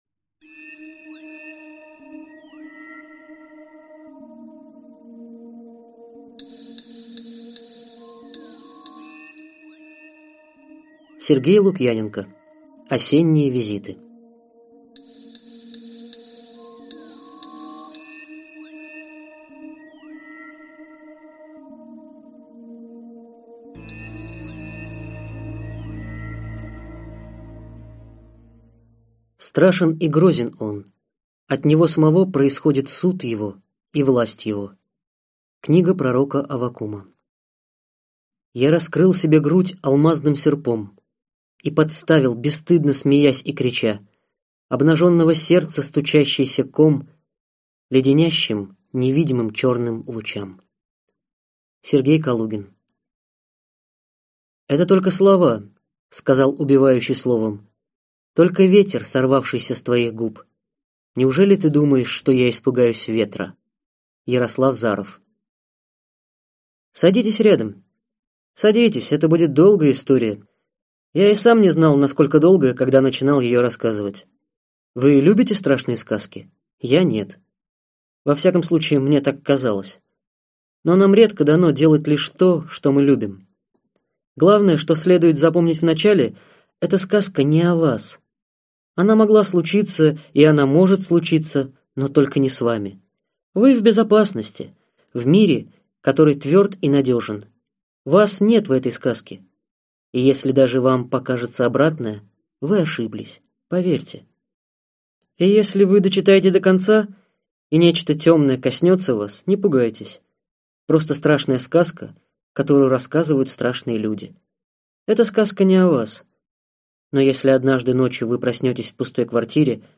Аудиокнига Визиты: Осенние визиты.